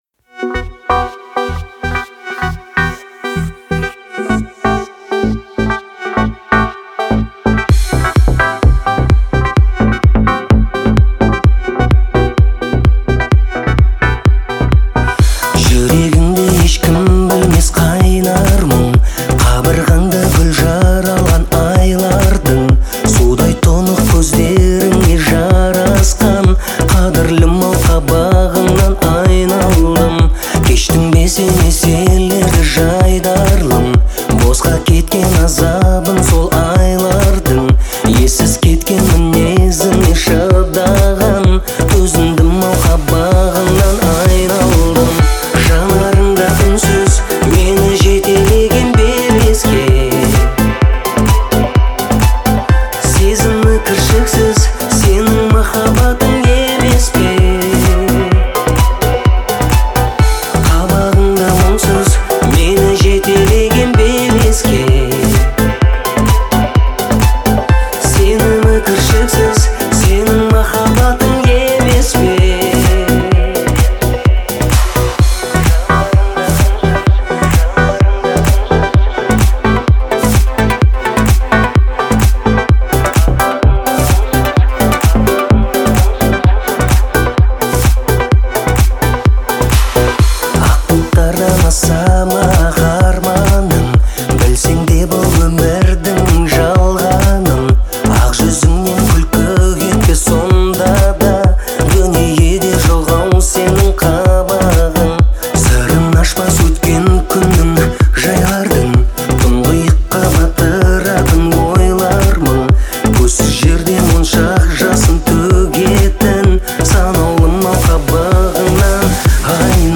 это эмоциональная песня в жанре казахского попа